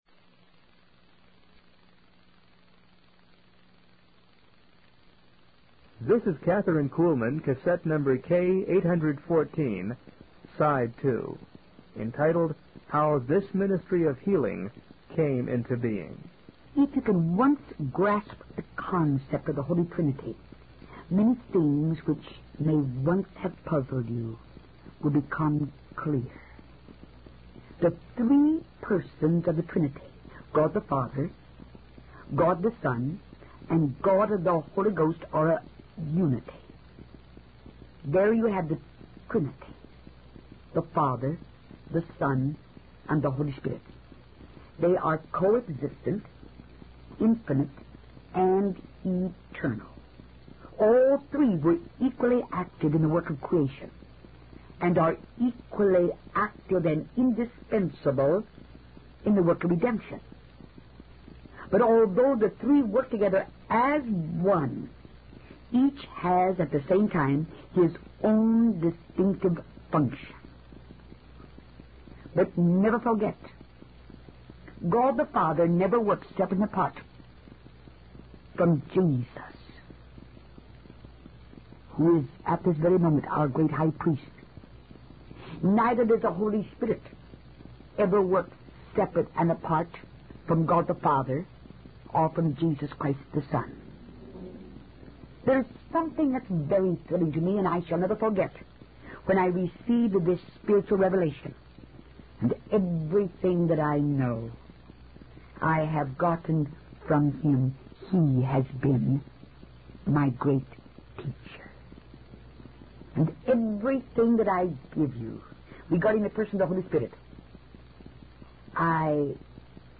In this sermon, the preacher emphasizes the importance of having faith in God and not focusing on the circumstances or problems we face.